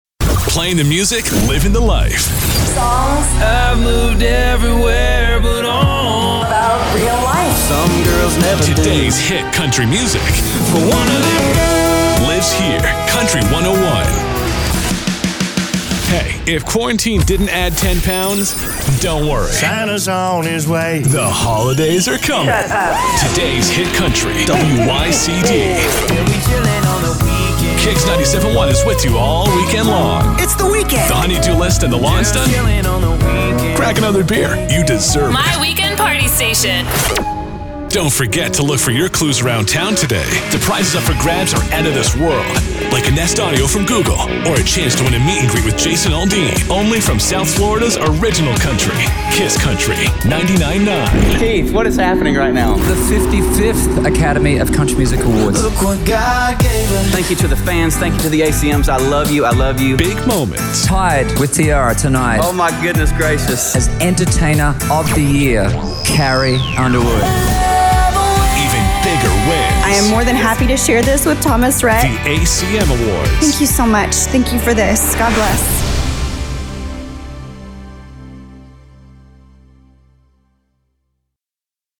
VO / Promo
COUNTRY